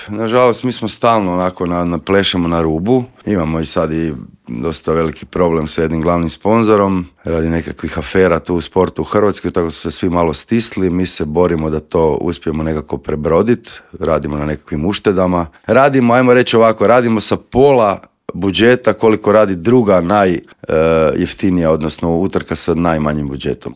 Osvrnuo se u intervjuu Media servisa na značaj utrke, utjecaj na gospodarstvo, stazu i na sve popratne događaje.